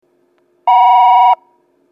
２番線発車ベル
（長野より）   長野より（跨線橋付近）の電子電鈴での収録です。